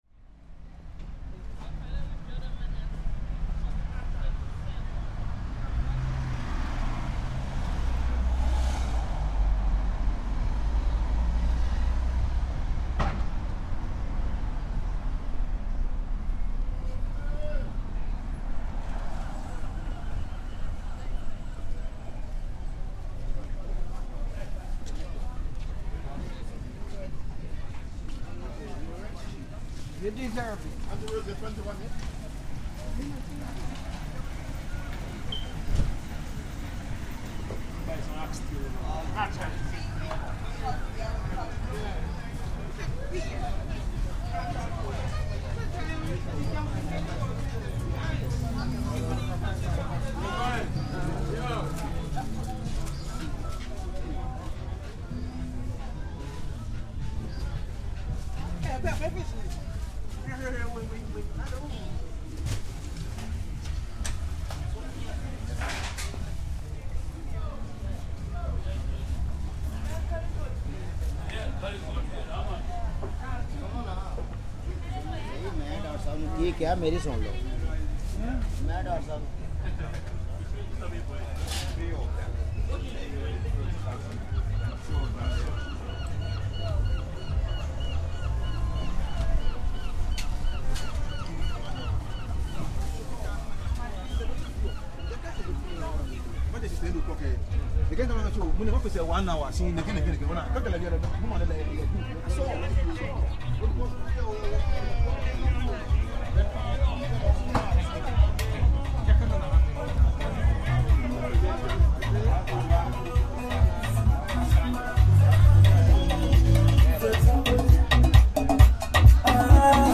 Ridley Road market, Dalston
A stroll through London's Ridley Road, a unique community intersection of Afro-Caribbean, Asian and European cultures. In the heart of the gentrifying Dalston - it may not exist for much longer in its current form.